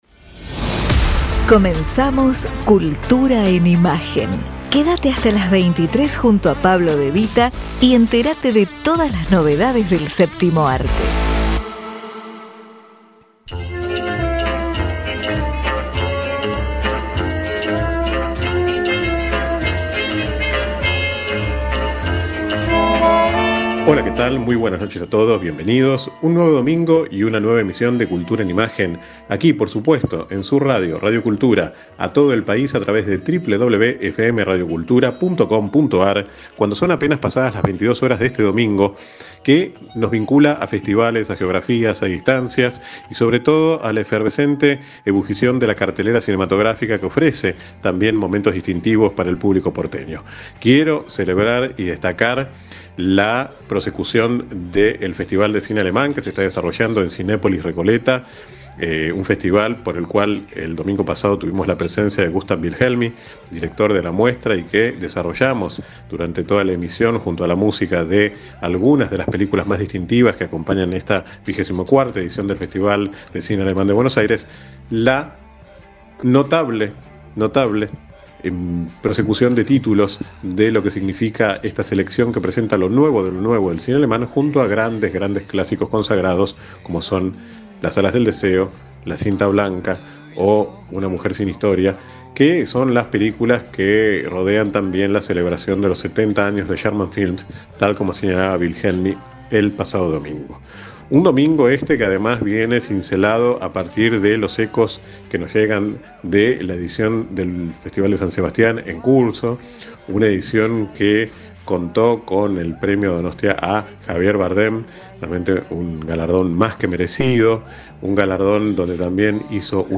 realizó una emisión especial desde el Festival Internacional de Cine de la Mujer Cinefem que en su 12 edición se realizó en la ciudad balnearia de Punta del Este en el Uruguay.